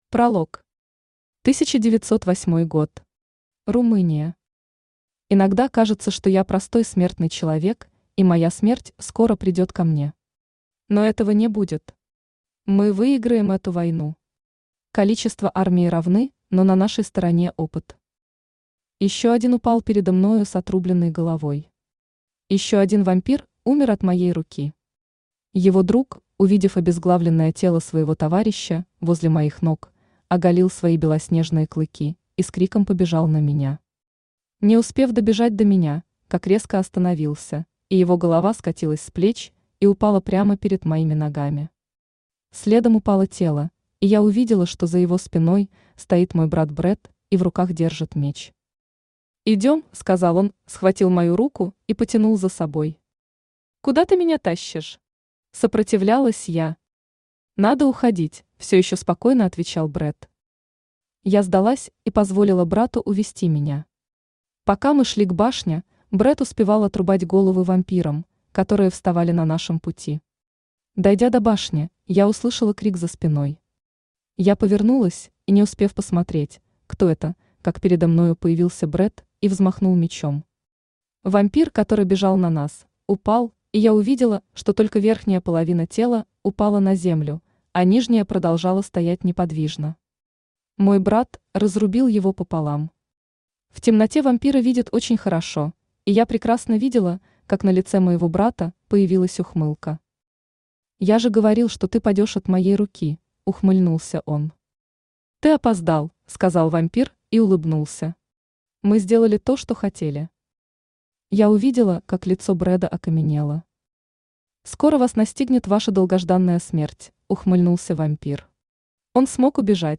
Aудиокнига Избранная Автор Алена Сав Читает аудиокнигу Авточтец ЛитРес.